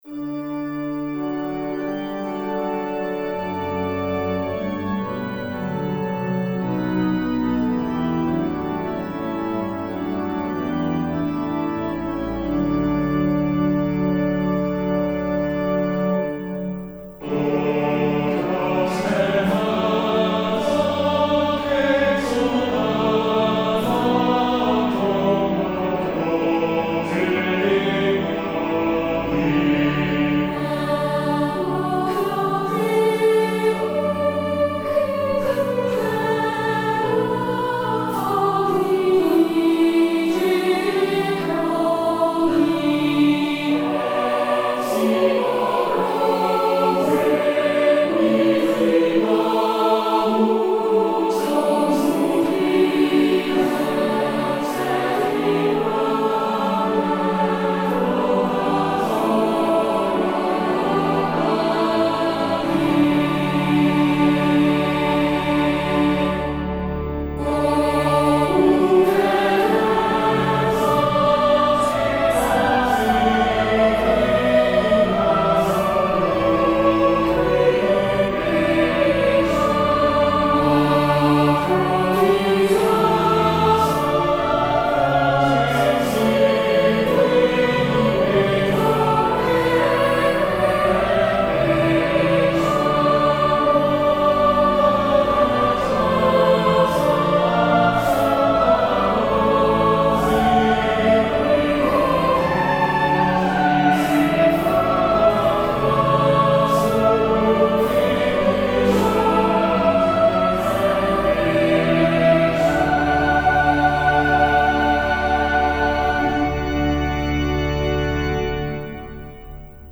Harmonized descant to the hymn tune MIT FREUDEN ZART